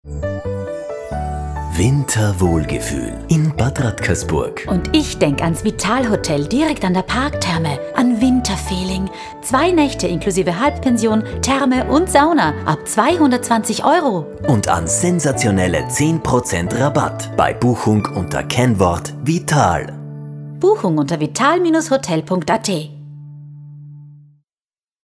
Für meine Aufnahmen im eigenen Studio habe ich einen professionellen Aufnahmeraum für beste Raumakustik und ungestörtes Arbeiten.
Radiospots
HFSpot_Vitalhotel_Winter18_25sek.mp3